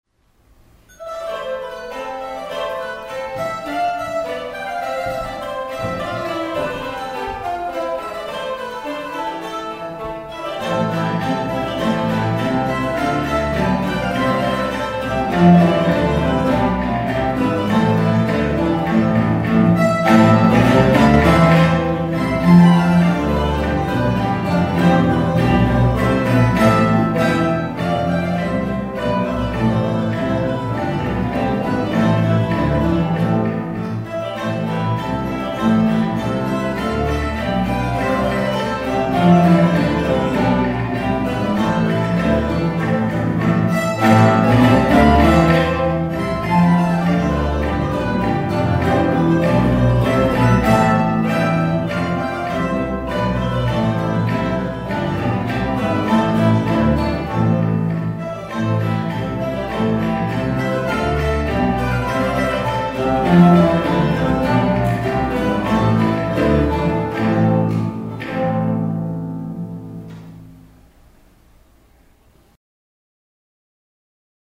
Orchestra Estudiantina
per orchestra di chitarre e mandolini
The Princess Royal (Allegro)